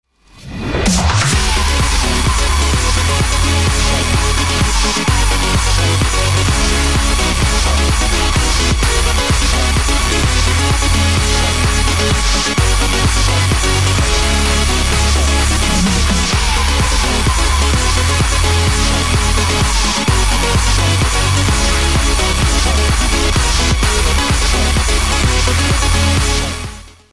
Главная » рингтоны на телефон » Клубные